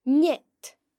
The slender N sound is made by pressing the tongue against the palate, and is made when the N occurs next to e or i in a word.
Additionally, this can be heard in nead (a nest):